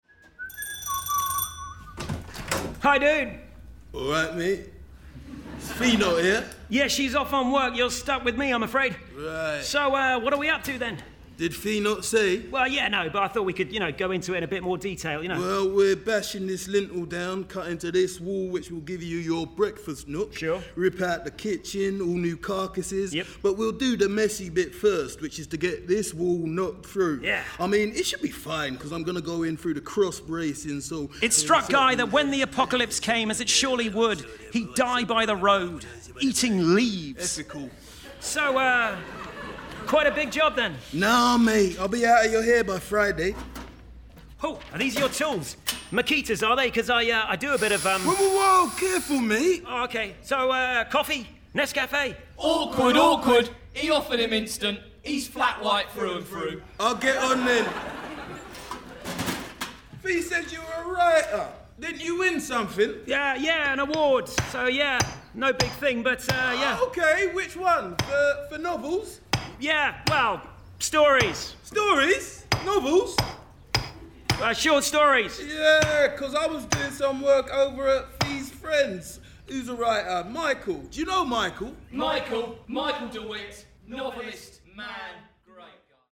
40/50's London/RP Characterful/Relaxed/Versatile
Victorian In the Wall (Rob – London)